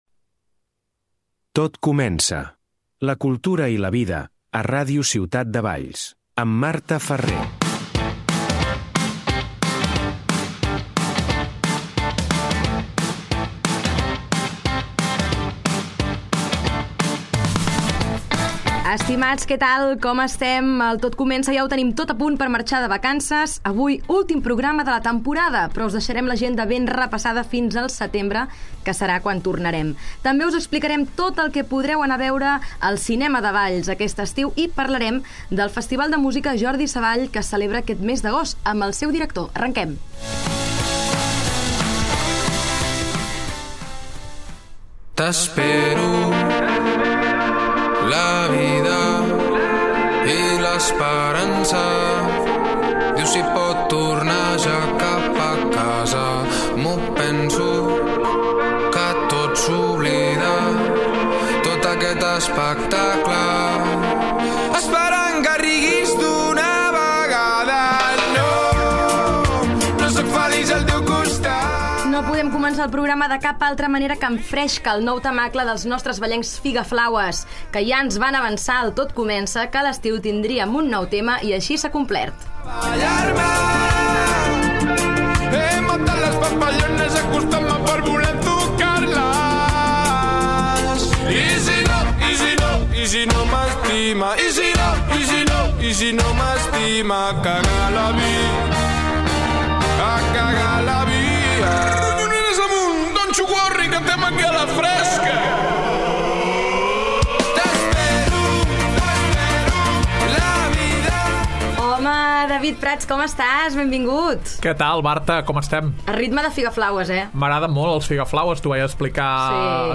A més, entrevistem